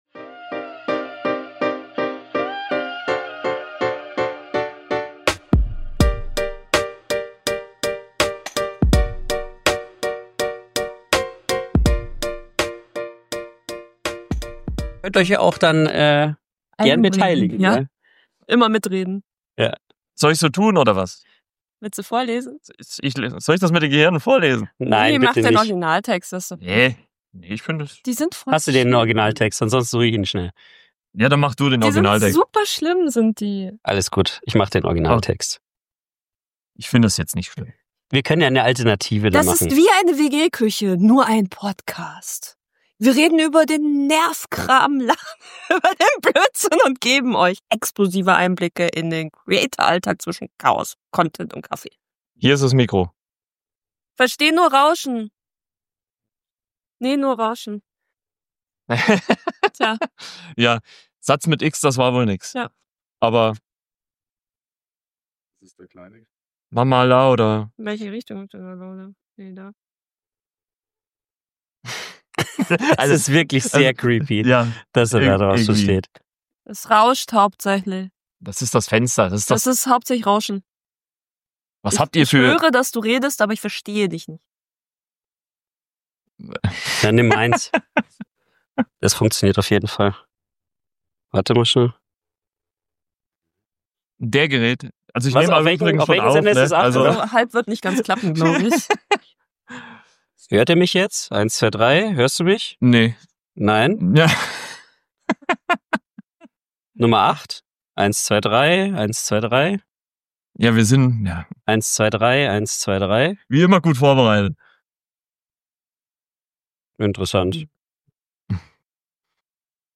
Unser Live Podcast von der Side Stage beim Podfluencer Festival 2025!
Live vom Podfluencer Festival 2025 in München
Drei Hosts im Gespräch